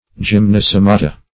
gymnosomata - definition of gymnosomata - synonyms, pronunciation, spelling from Free Dictionary
Search Result for " gymnosomata" : The Collaborative International Dictionary of English v.0.48: Gymnosomata \Gym`no*so"ma*ta\ (j[i^]m"n[-o]*s[=o]"m[.a]*t[.a] or j[i^]m"n[-o]*s[o^]m"[.a]*t[.a]), n. pl.
gymnosomata.mp3